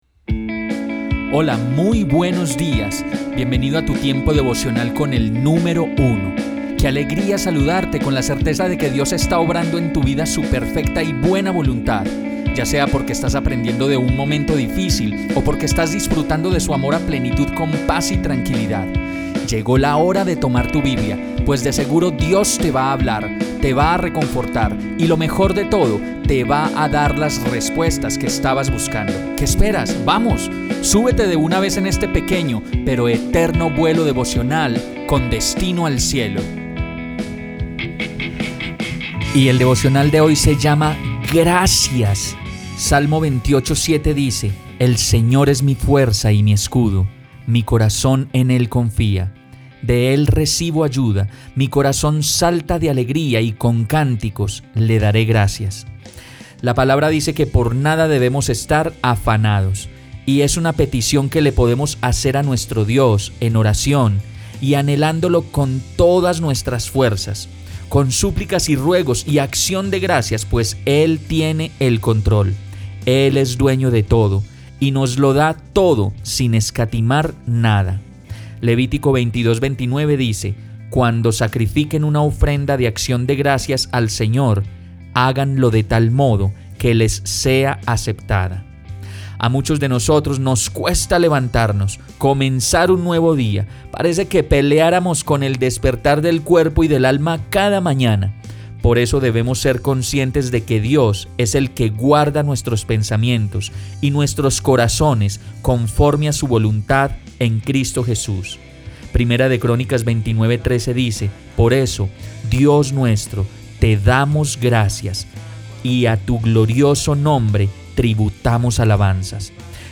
Devocional. Tu Tiempo con el Número Uno…Graciassss… 7 Septiembre